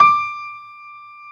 55p-pno31-D5.wav